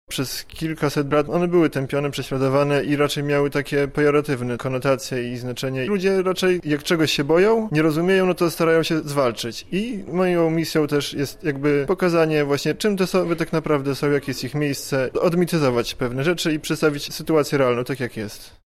przyrodnik amator mówi